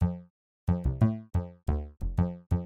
快乐的钢琴循环
描述：可用于lofi或流行音乐节拍。
标签： 90 bpm Pop Loops Piano Loops 1.80 MB wav Key : C Logic Pro
声道立体声